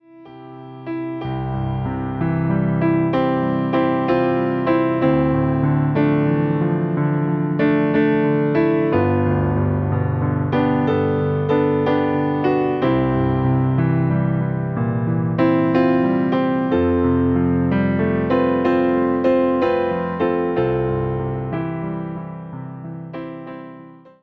Wersja demonstracyjna:
62 BPM
C – dur